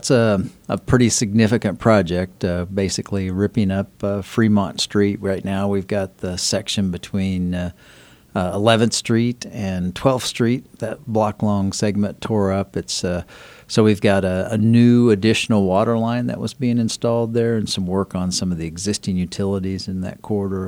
Manhattan city manager Ron Fehr says the project is significant and says a new additional waterline was recently installed.